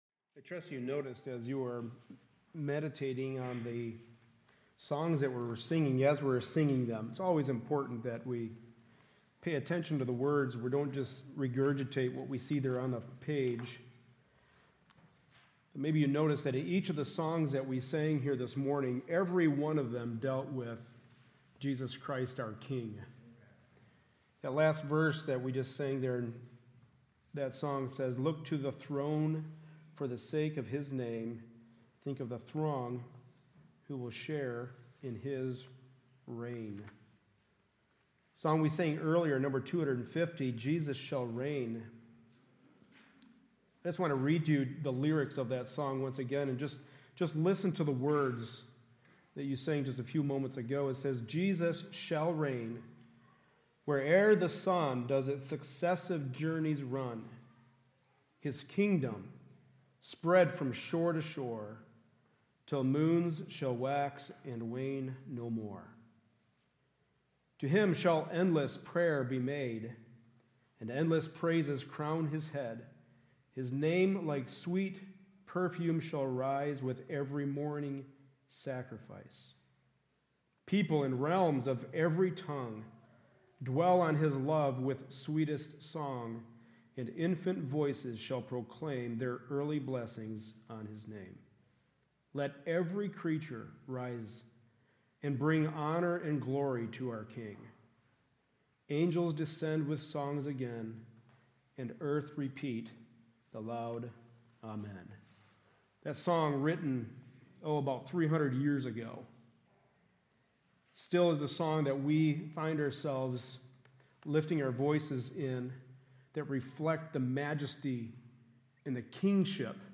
Psalm 24 Service Type: Sunday Morning Service Related Topics